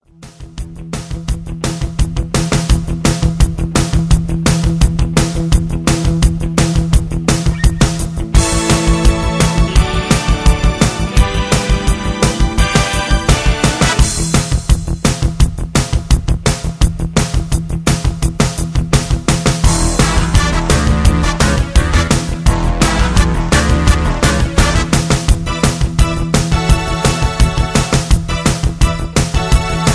Tags: karaoke , backing tracks , sound tracks